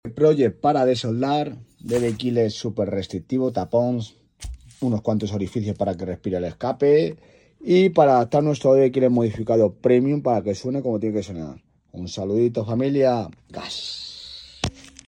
¡El resultado es un sonido más potente y un petardeo increíble!
The result is a more powerful sound and amazing crackling!